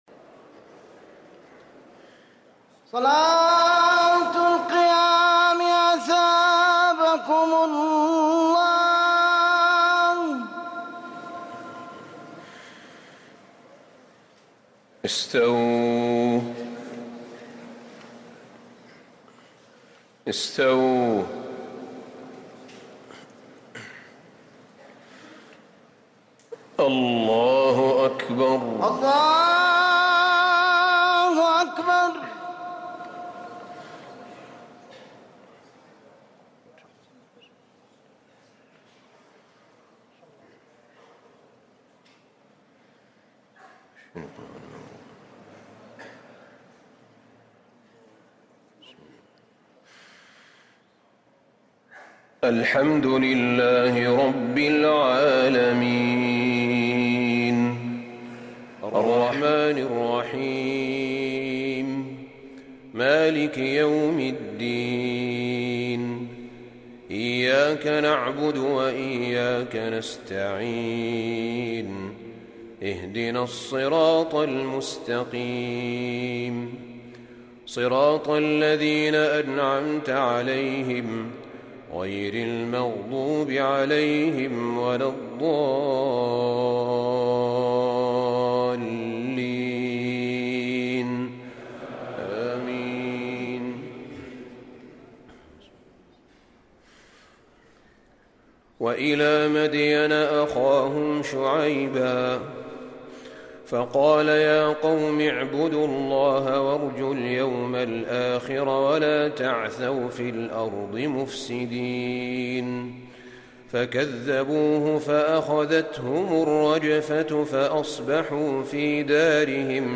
تراويح ليلة 24 رمضان 1444هـ من سورتي العنكبوت (36-69) و الروم (1-32) | taraweeh 24 st night Ramadan 1444H Surah Al-Ankaboot and Ar-Room > تراويح الحرم النبوي عام 1444 🕌 > التراويح - تلاوات الحرمين